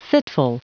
Prononciation du mot fitful en anglais (fichier audio)
Prononciation du mot : fitful